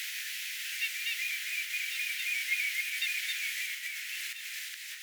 hiukan sitä punajalkaviklon
liromaista soidinääntelyä
sita_punajalkaviklon_liromaista_laulua_ilmeisestikin.mp3